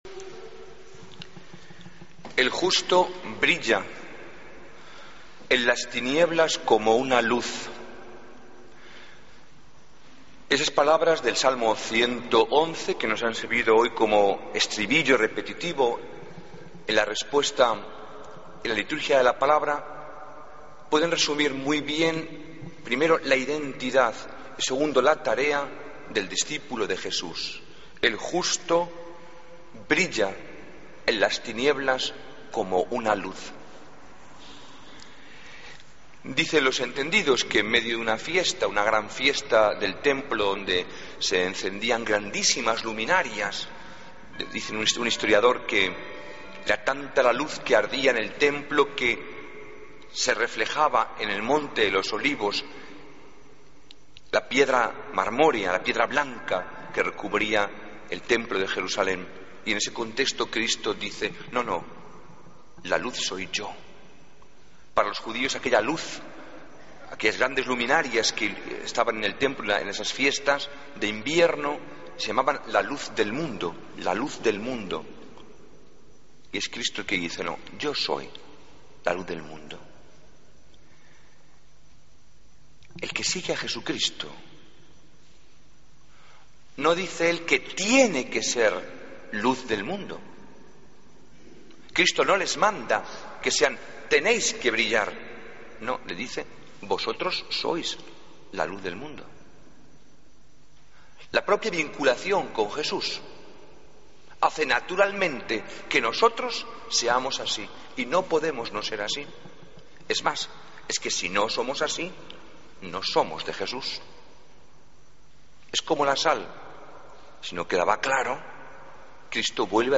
Homilía del 9 de Febrero de 2014